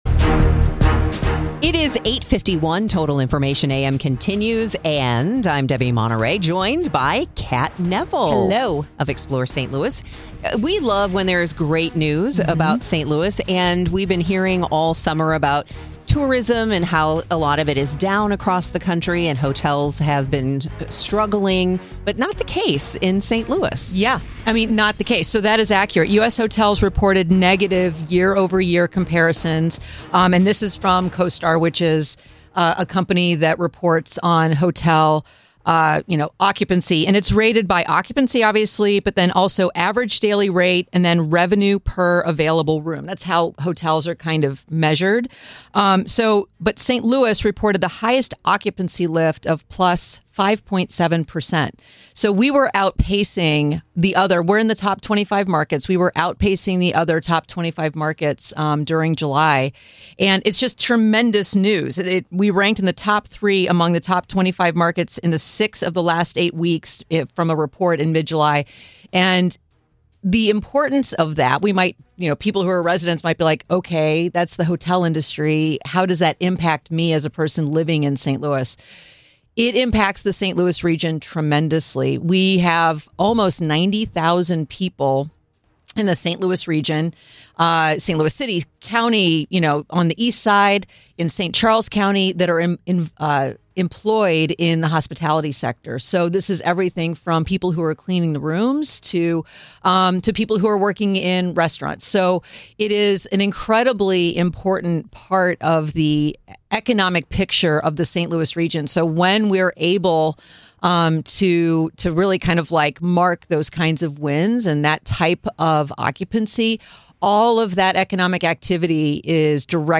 With up-to-the-minute news, information, traffic and weather together and sports reports.